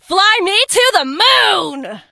janet_ulti_vo_06.ogg